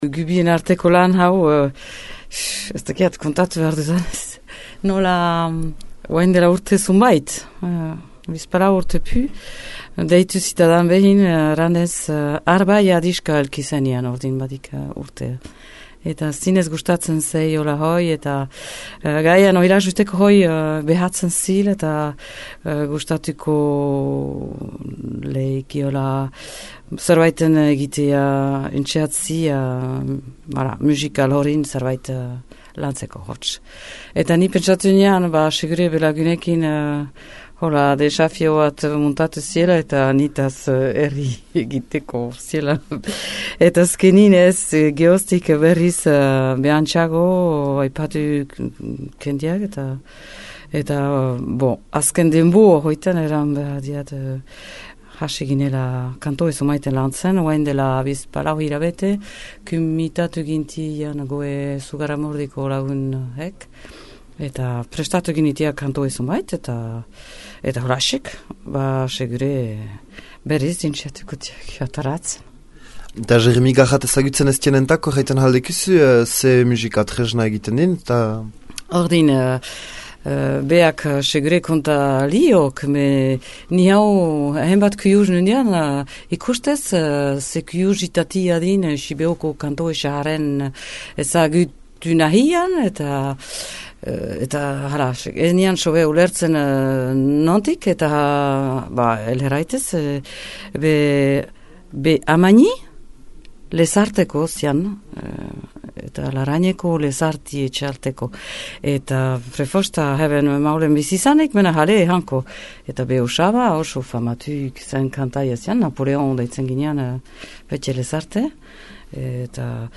eta alkarrizketaren erdian, Zinka ostatüan grabatü kantore bat ere entzüten ahalko düzüe.